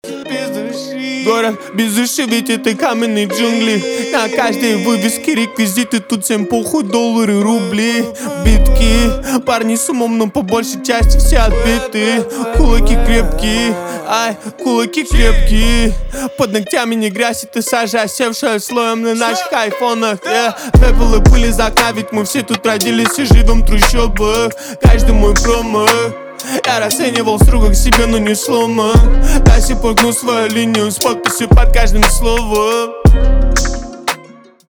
русский рэп , гитара
басы
грустные , жесткие